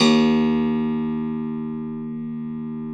53q-pno03-D0.wav